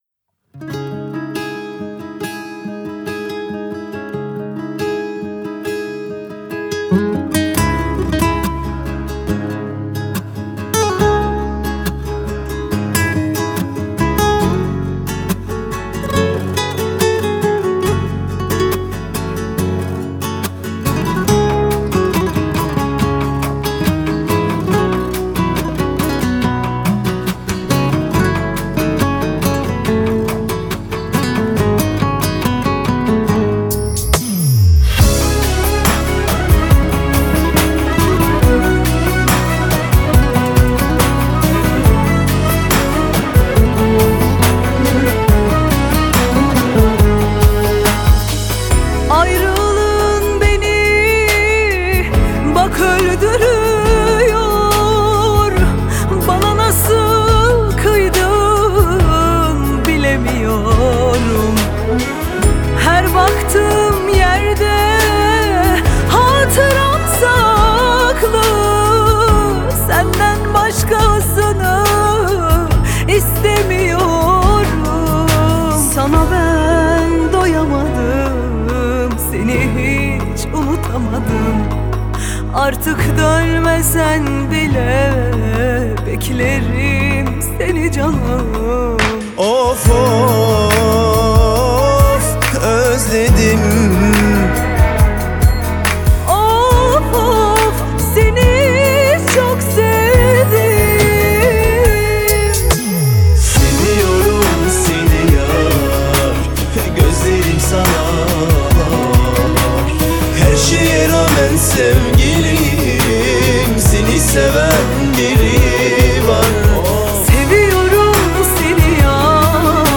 آهنگ ترکیه ای آهنگ غمگین ترکیه ای آهنگ هیت ترکیه ای ریمیکس